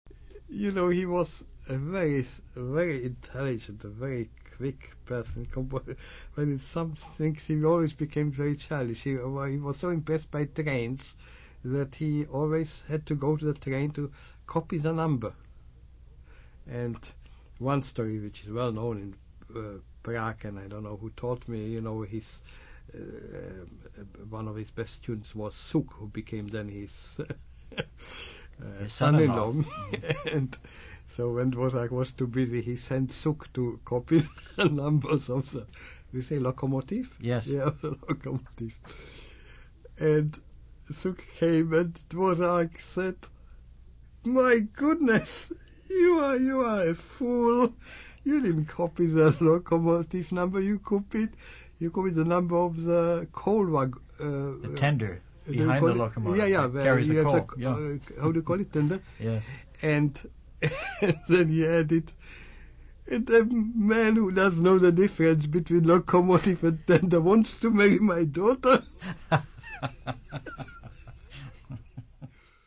Click here to listen to Morawetz describe his experiences observing orchestra rehearsals.